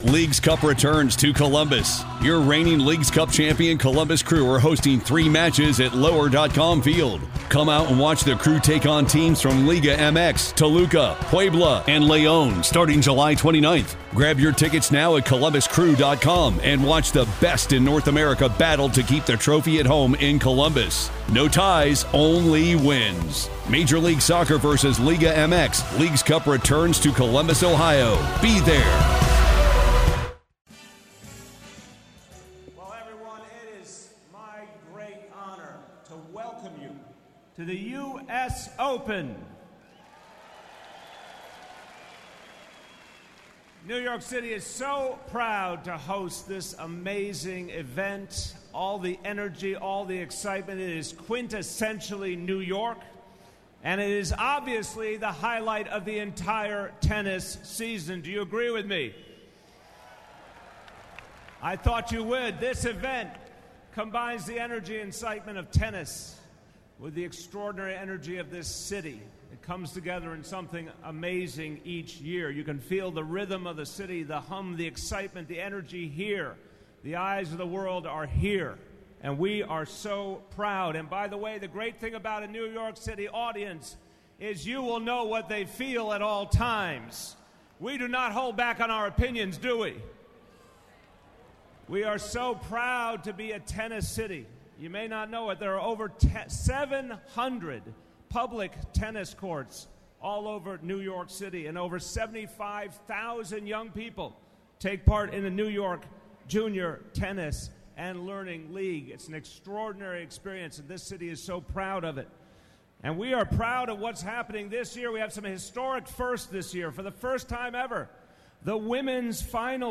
2015 US Open Opening Ceremony
New York City Mayor Bill de Blasio and USTA President Katrina Adams kick off the US Open festivities.